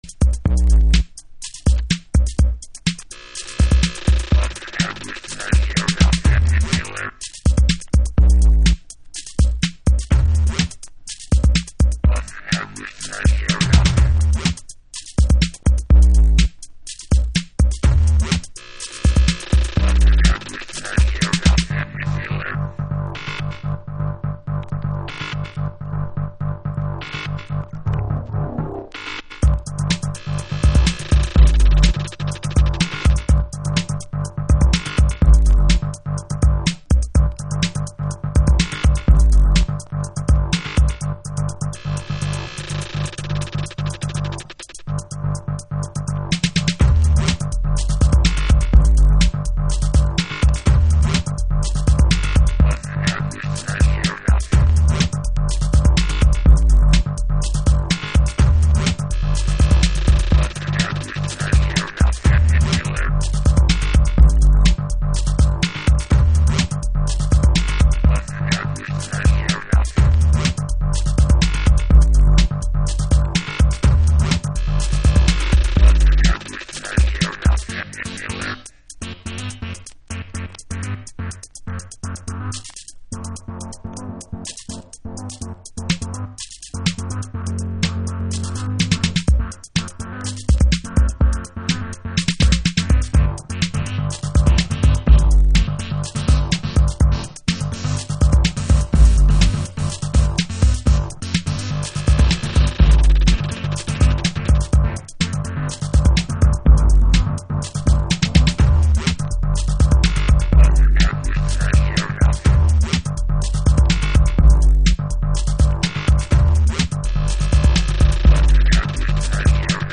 House / Techno
UKベースのデュオによるエレクトロ。